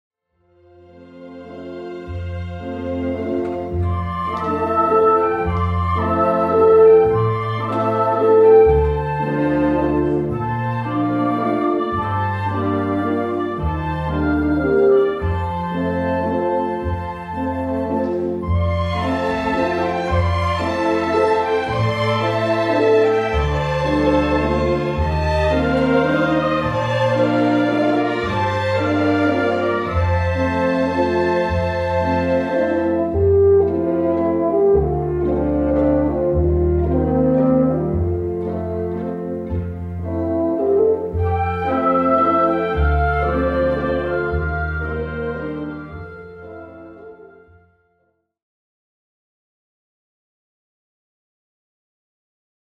01 1. Truce of Carols overture web excerpt.mp3